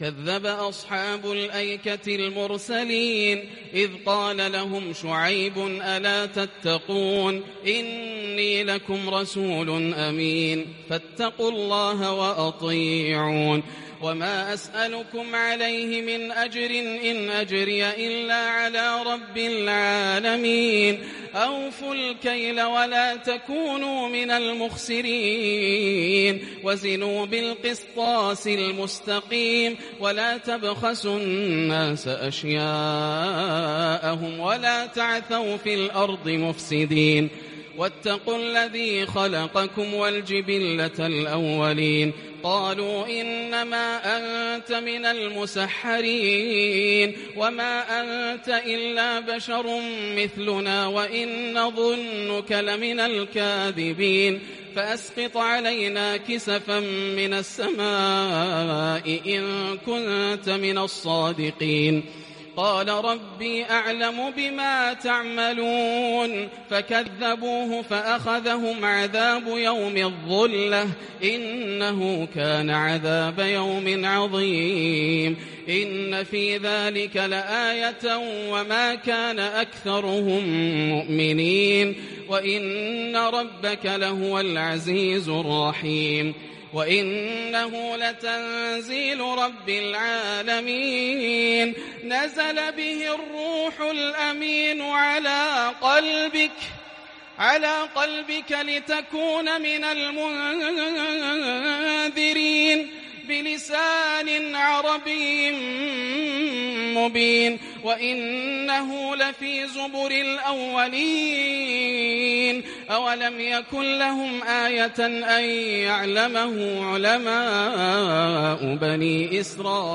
وَإِنَّ رَبَّكَ لَهُوَ الْعَزِيزُ الرَّحِيمُ - تلاوة تفوق الوصف من ليلة 23 > الروائع > رمضان 1441هـ > التراويح - تلاوات ياسر الدوسري